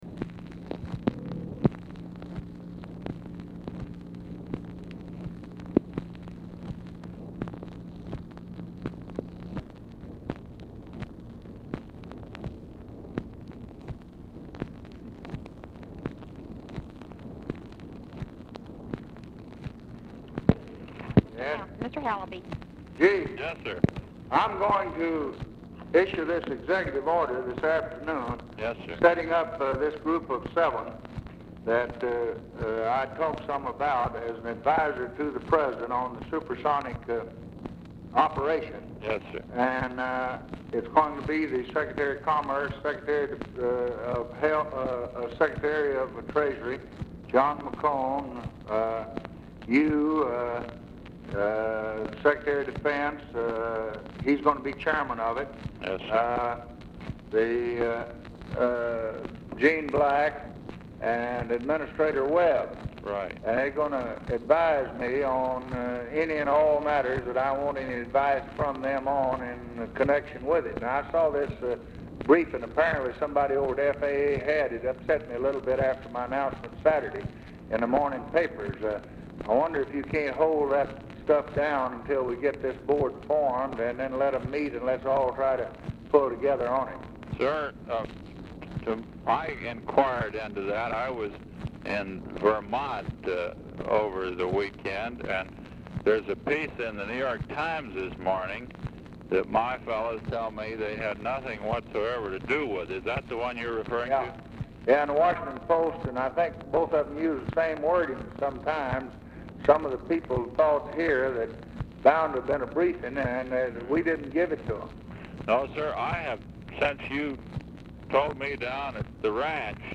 Telephone conversation # 2814, sound recording, LBJ and NAJEEB HALABY, 4/1/1964, 4:45PM | Discover LBJ
Format Dictation belt
Location Of Speaker 1 Oval Office or unknown location